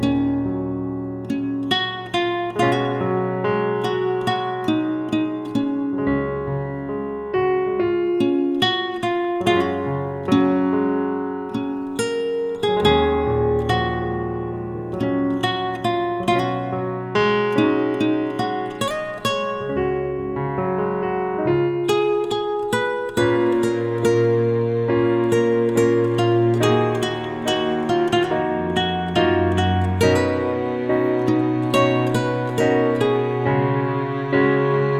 # Instrumental